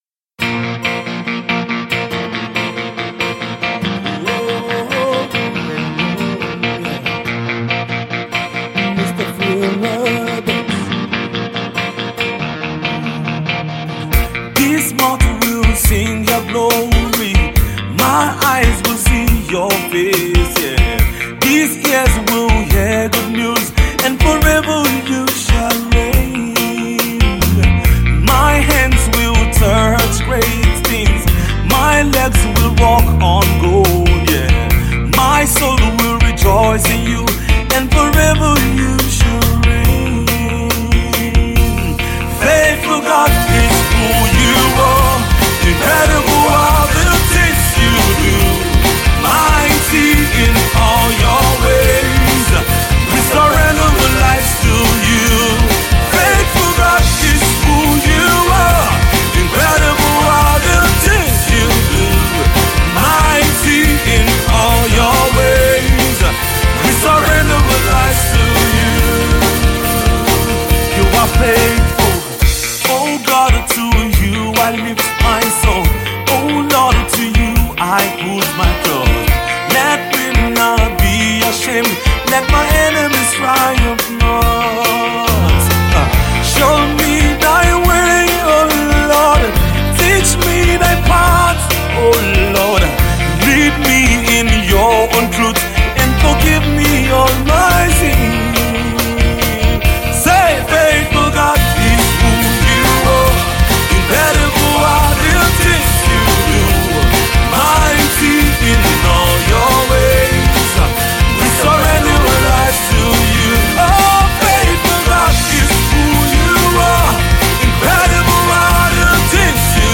gospel rock